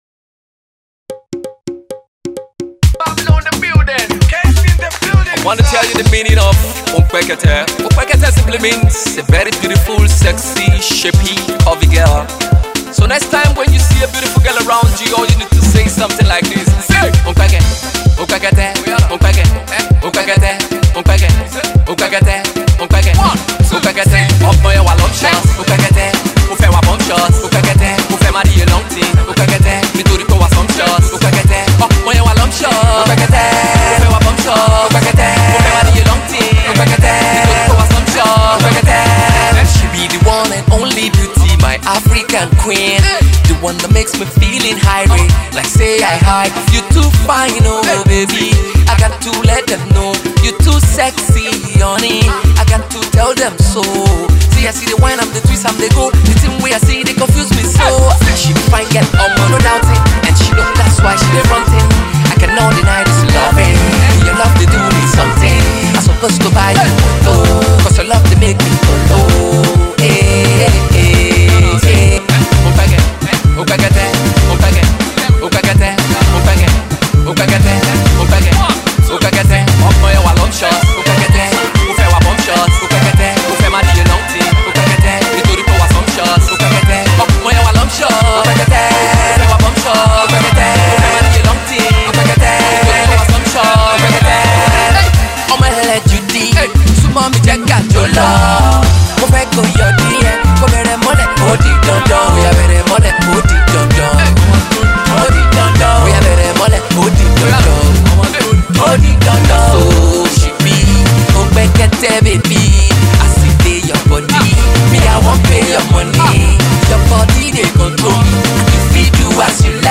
features a South African rapper
funky dance tune